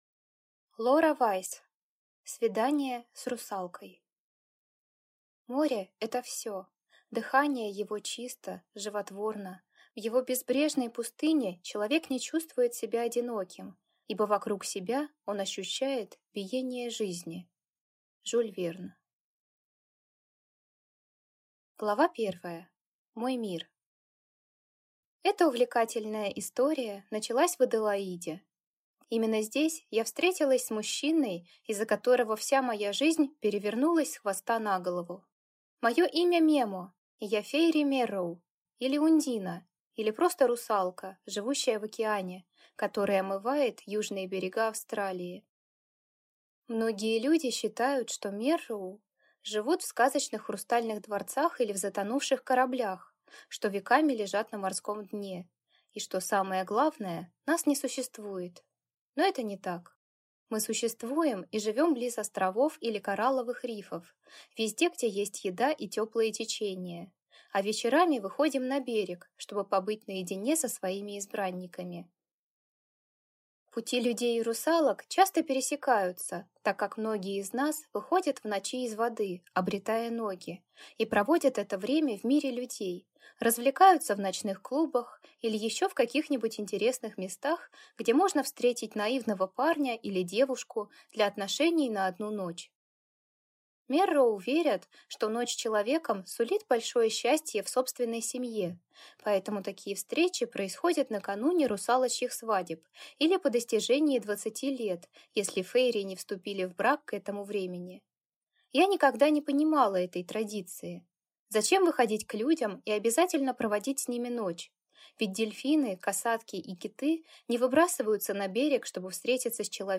Аудиокнига Свидание с русалкой | Библиотека аудиокниг